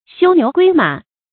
休牛歸馬 注音： ㄒㄧㄨ ㄋㄧㄨˊ ㄍㄨㄟ ㄇㄚˇ 讀音讀法： 意思解釋： 亦作「休牛散馬」。